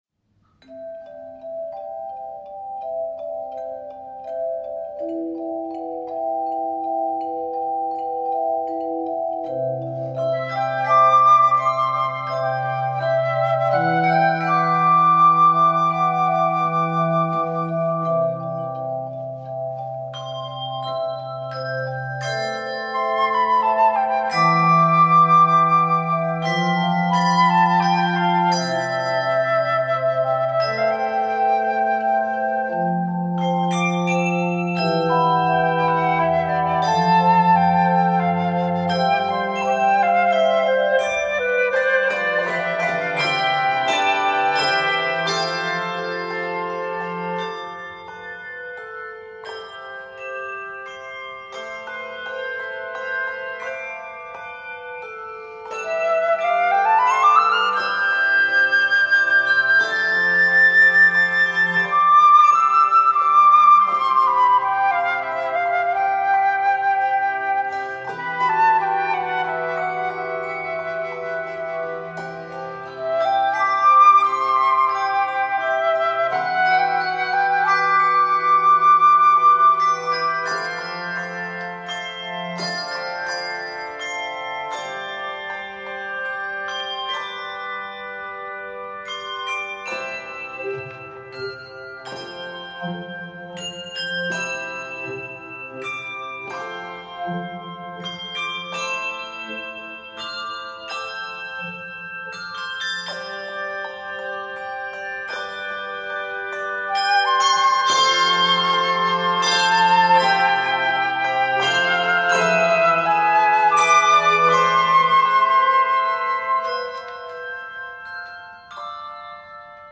handchimes and flute